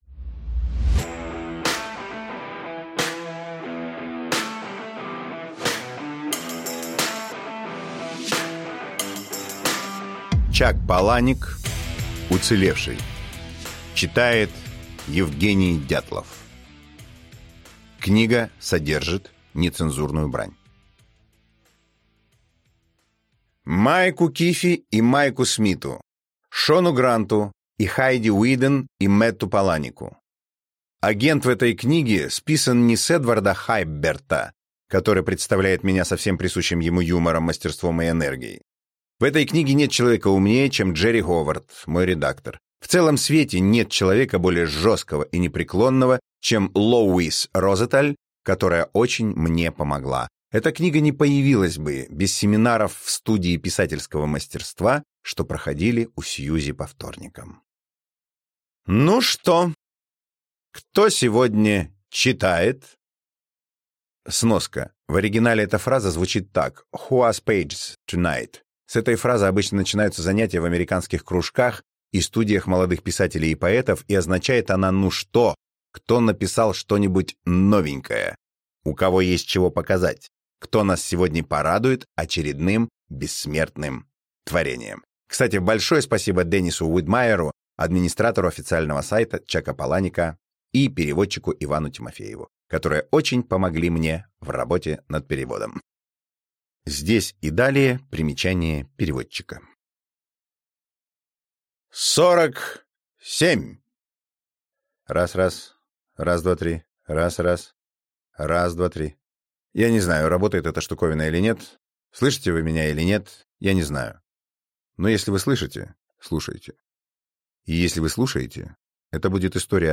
Аудиокнига Уцелевший | Библиотека аудиокниг